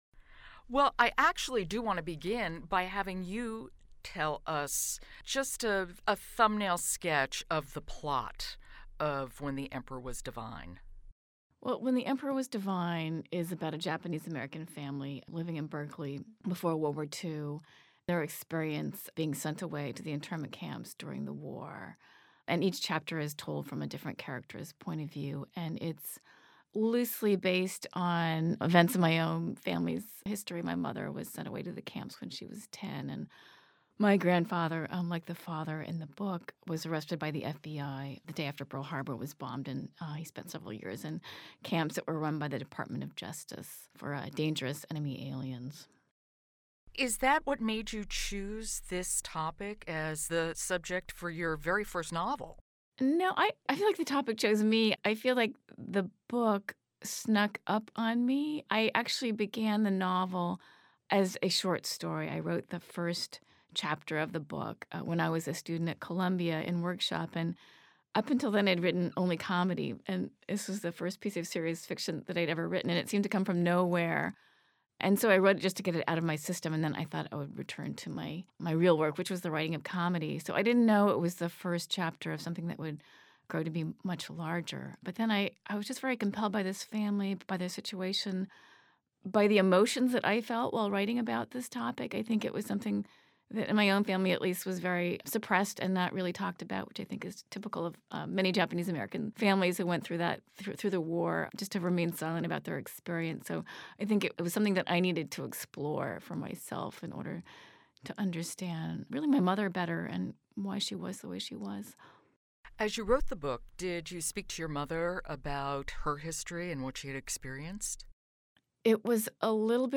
NEA Big Read: An Interview with Julie Otsuka